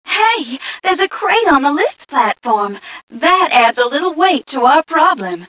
mission_voice_t7ca015.mp3